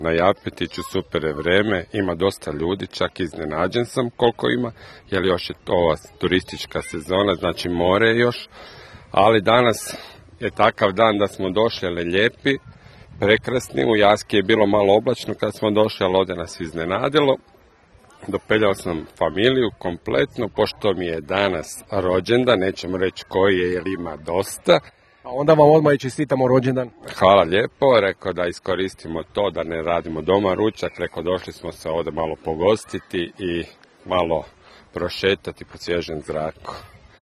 Bili smo na ‘Japu’ i snimili dojmove posjetitelja